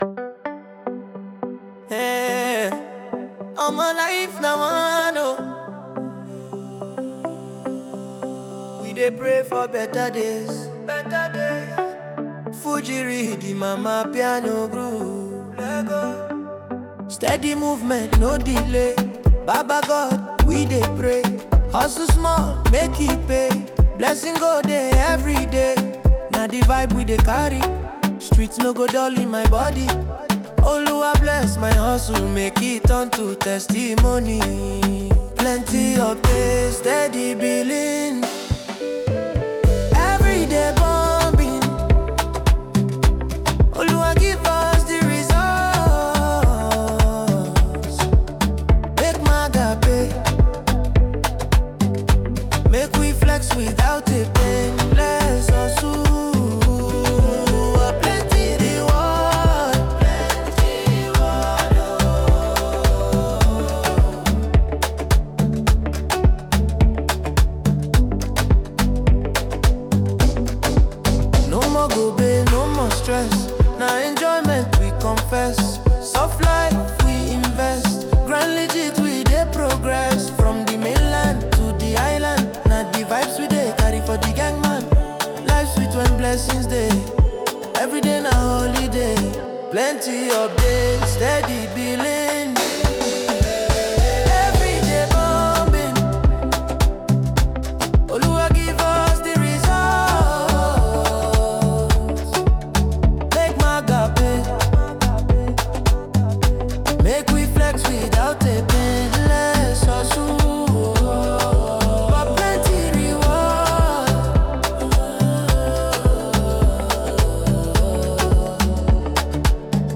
blending smooth melodies with raw, authentic storytelling.
delivers his verses with a laid-back, yet determined flow